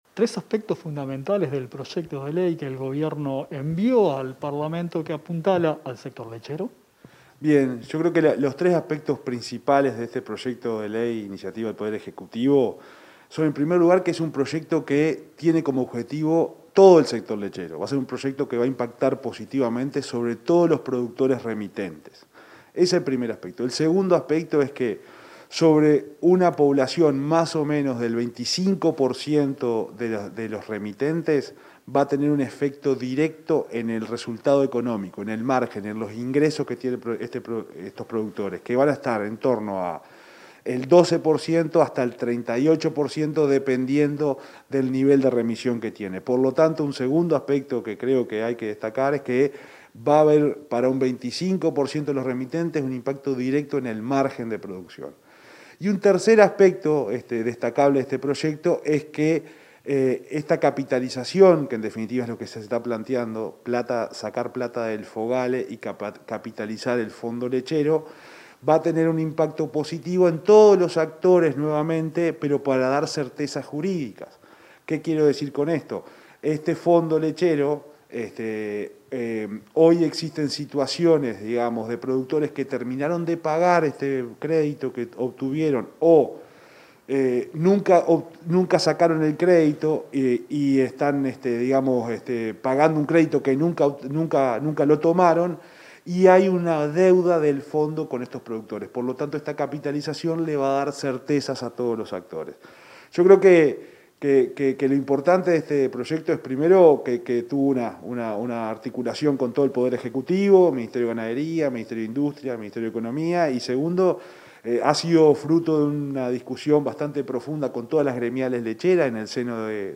Entrevista al subsecretario de Ganadería, Ignacio Buffa